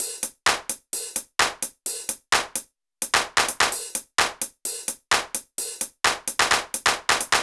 BAL Beat - Mix 11.wav